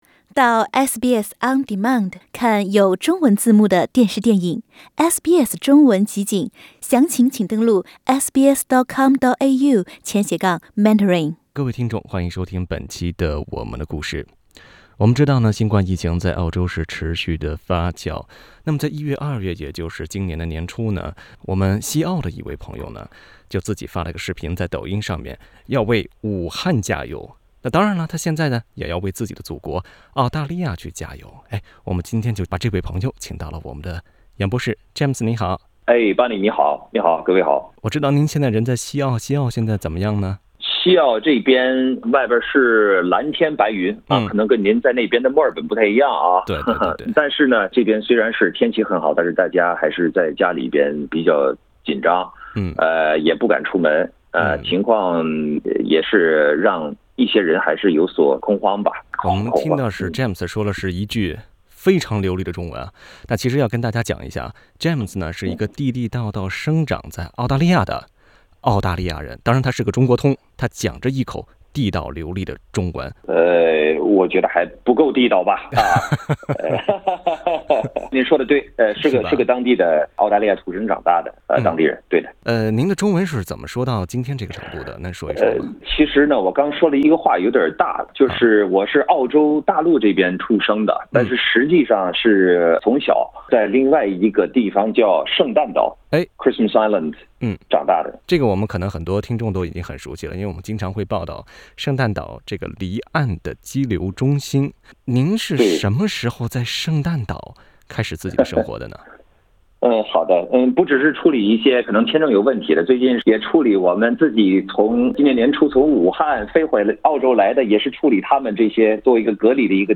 還學了一口地道的普通話。他要同您講述自己跨文化的成長經厤，也想借此機會“昭告天下”：疫情下的澳大利亞，種族主義沒有容身之地。